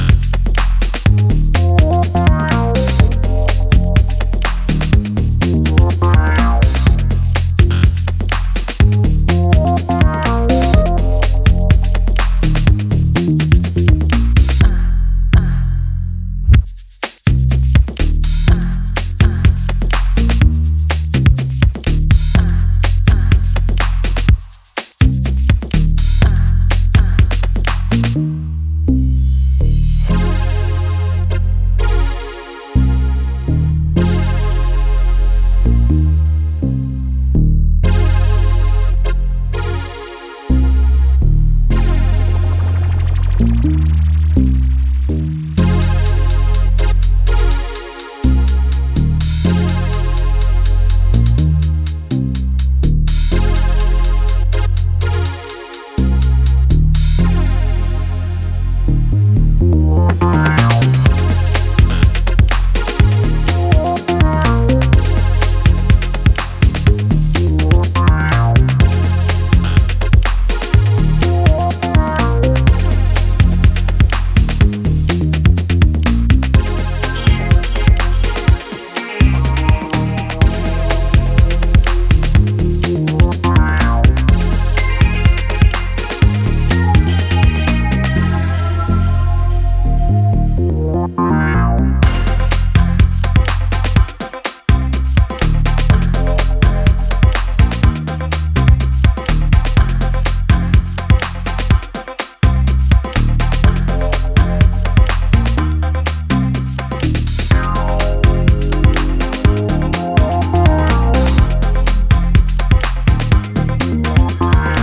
offering a rich blend of electronic sounds